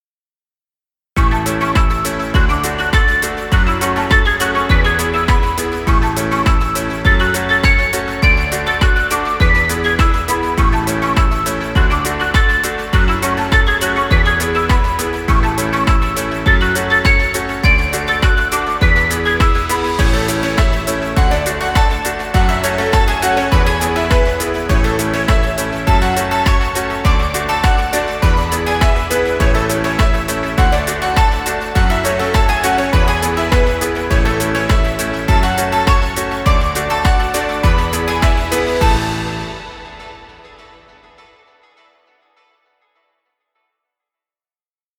Children happy music. Background music Royalty Free.